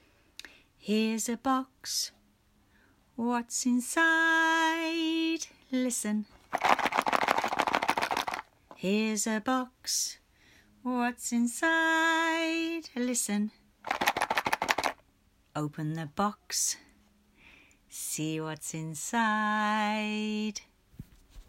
Play a listening game...put some acorns or conkers or sycamore seeds in different boxes and sing our song 'Here's a box, what's inside? Listen!' (a scratch recording of the song can be found